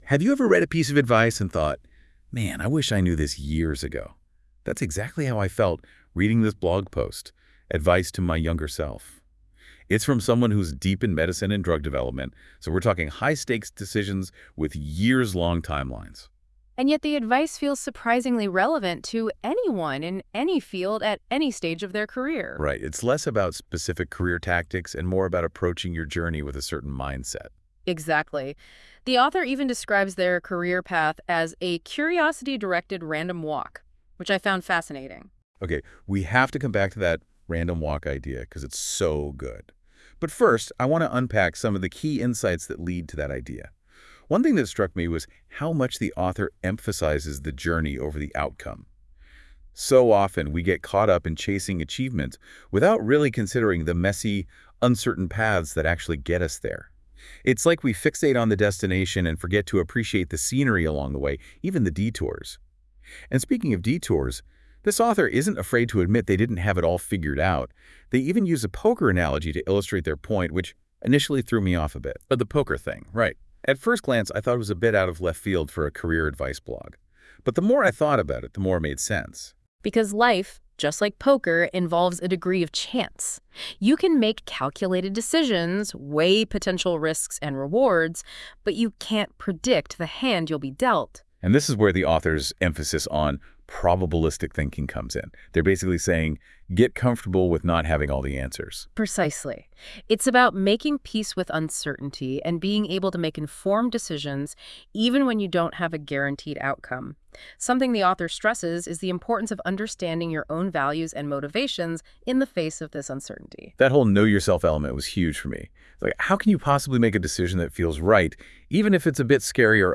This blog is a reflection on the traits of successful people (current tense), with advice I wish I had received as I was navigating the early stages of my career (subjunctive and past tenses). For fun, I used Google’s “Illuminate” app to turn the text below into a 7-minute podcast (podcast audio link